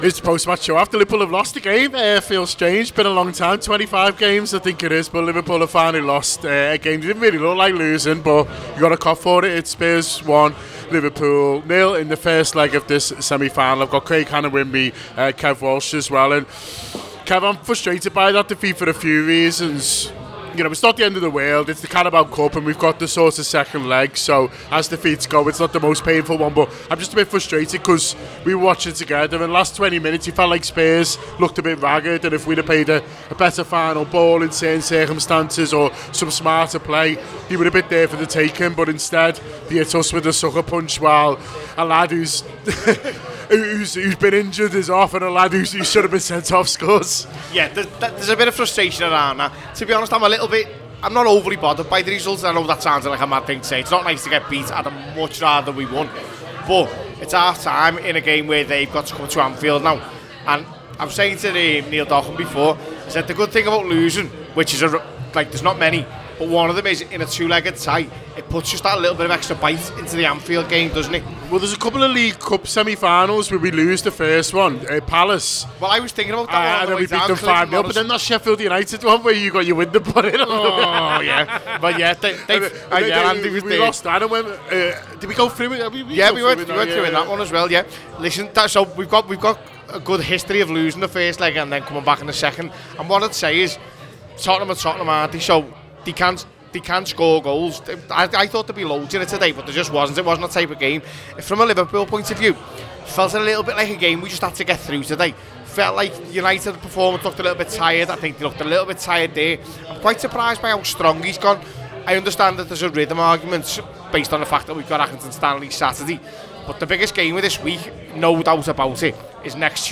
The Anfield Wrap’s post-match reaction podcast after Tottenham Hotspur 1 Liverpool 0 in first leg of the Carabao Cup semi-final.